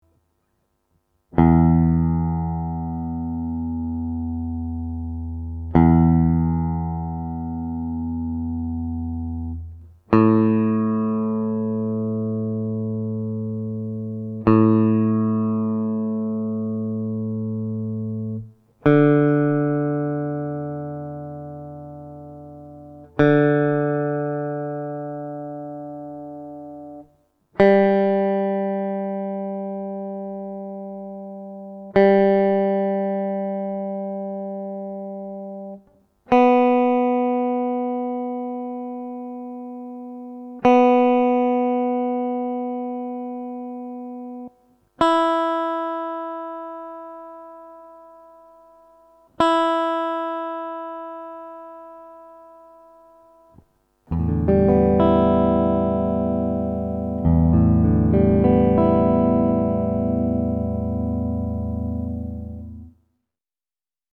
Leersaiten Audio-Vorlage zum Stimmen
E-A-D-G-H-E-Referenz-Aufnahme.mp3